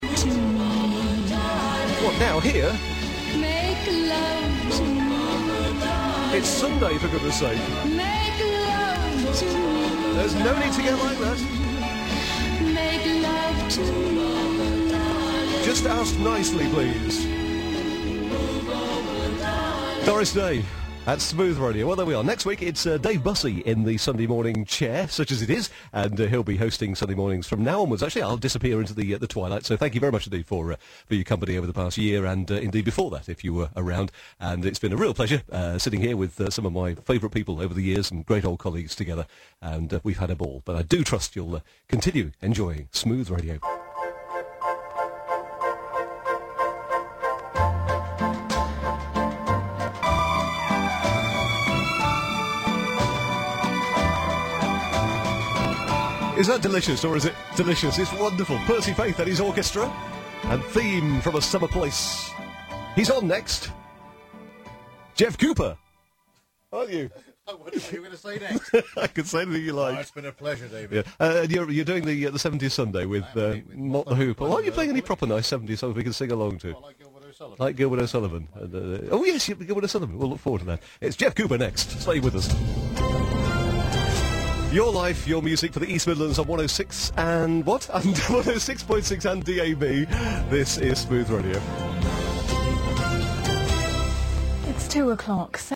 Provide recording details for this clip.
last show on Smooth East Midlands